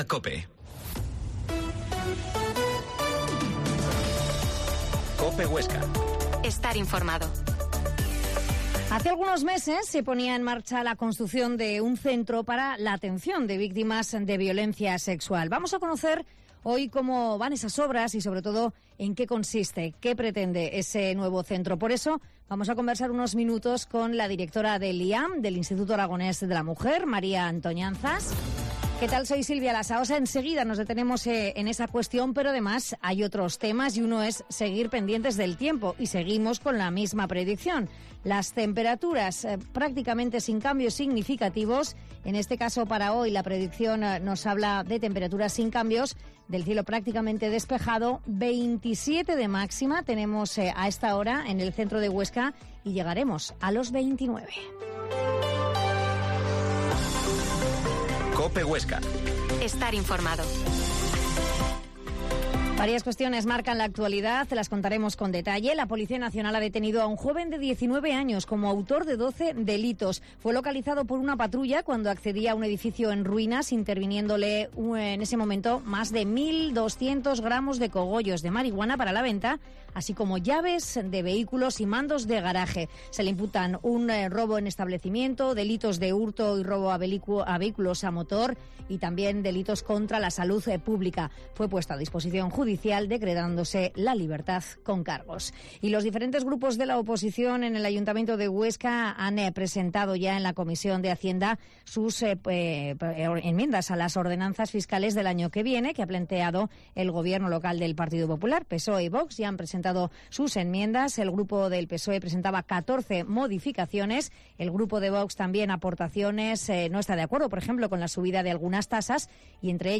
Mediodia en COPE Huesca 13.50 Entrevista a la Directora del IAM, María Antoñanzas